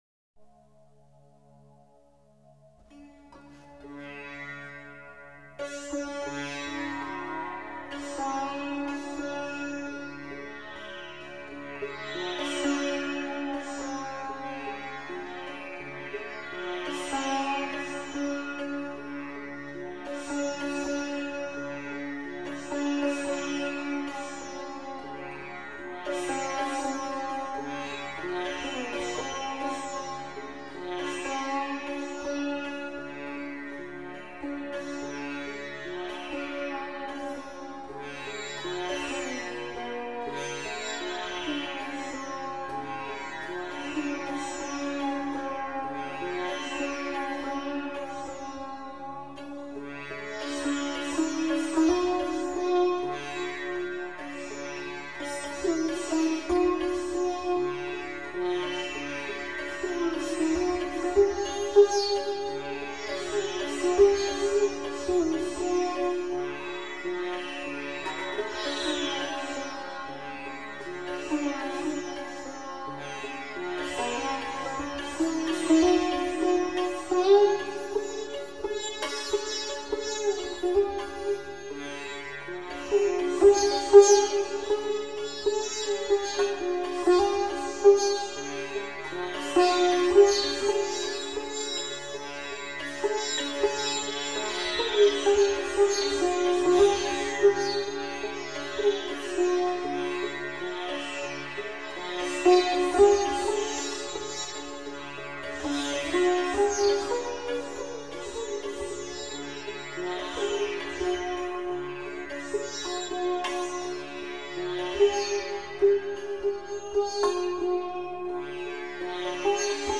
Нью эйдж
Медитативная музыка